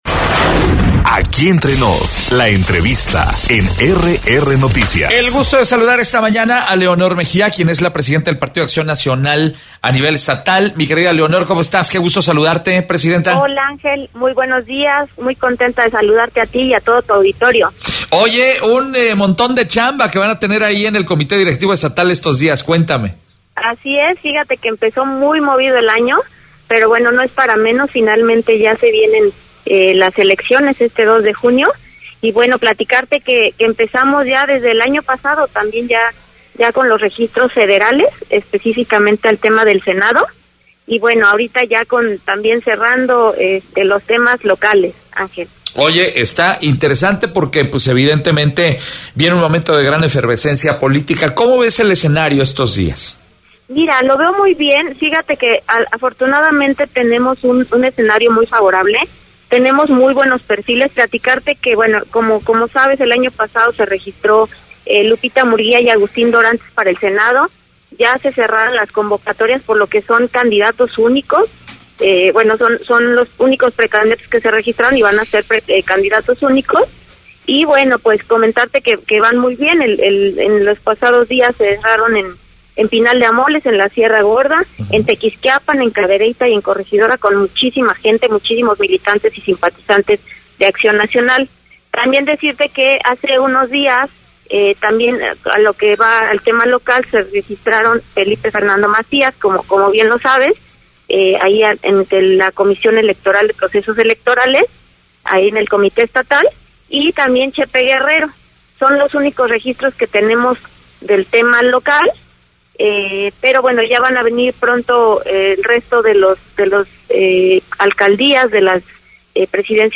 Entrevistas Podcast Continúa proceso de registro de candidatos en el PAN